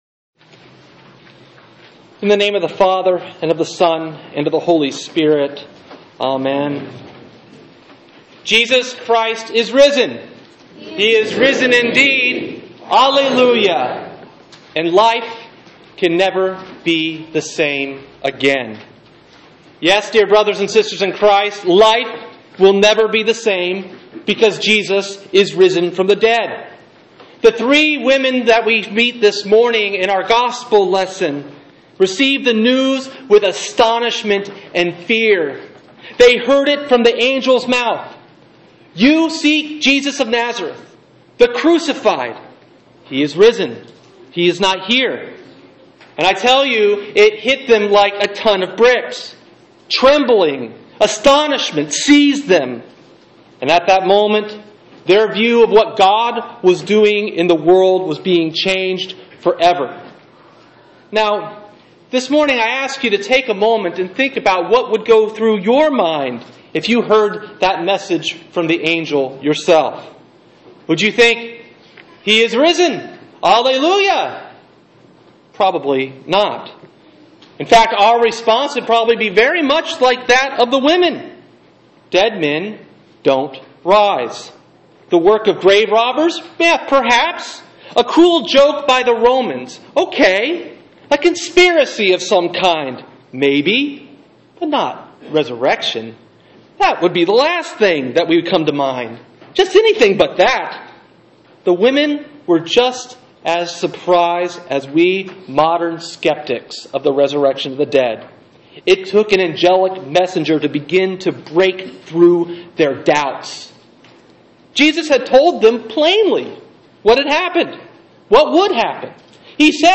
Sermon: Easter Day Mark 16:1-8